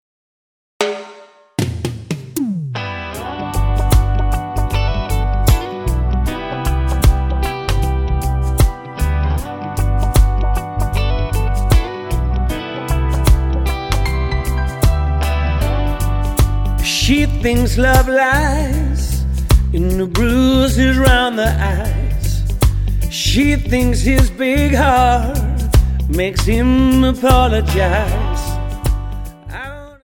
Tonart:G Multifile (kein Sofortdownload.
Die besten Playbacks Instrumentals und Karaoke Versionen .